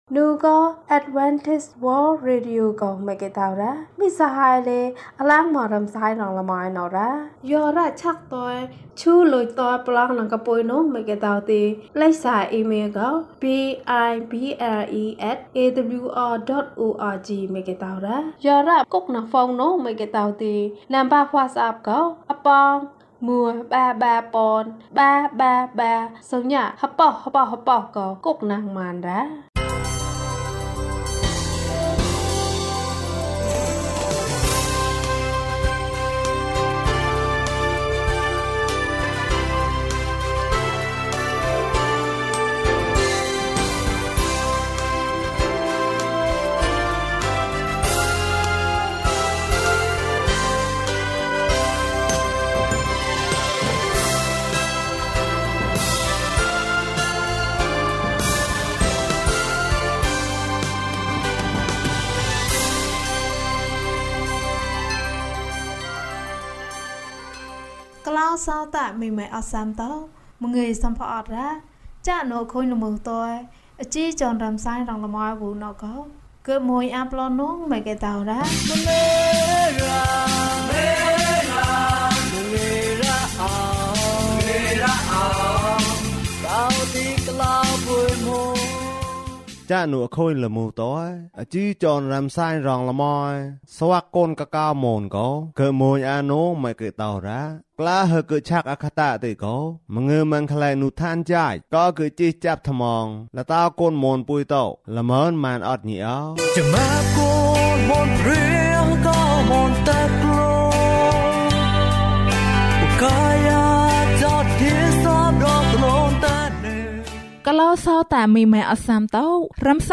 ငါဆင်းသောအခါ ယေရှုနှင့်အတူ။ ကျန်းမာခြင်းအကြောင်းအရာ။ ဓမ္မသီချင်း။ တရား‌ဒေသနာ။